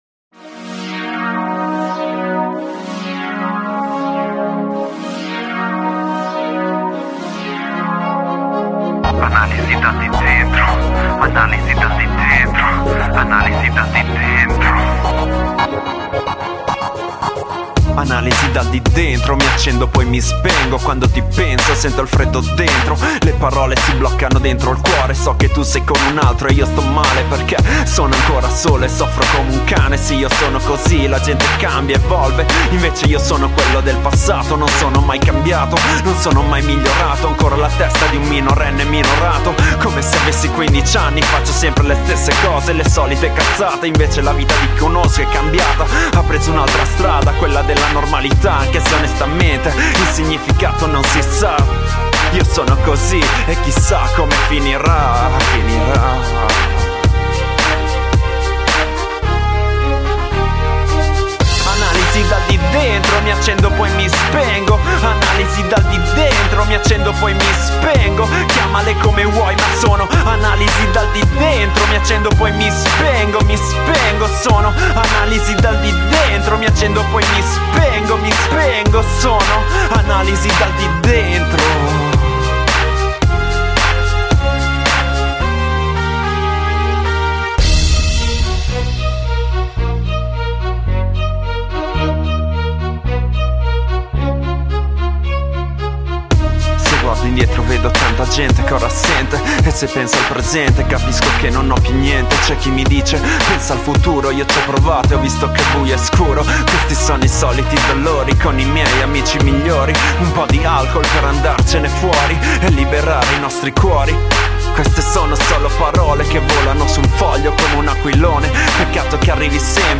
GenereHip Hop